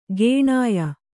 ♪ gēṇāya